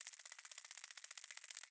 На этой странице собраны редкие записи, демонстрирующие разнообразие акустических сигналов этих многоножек: от шуршания ножками до стрекотания в моменты опасности.
Сколопендра - Альтернативный вариант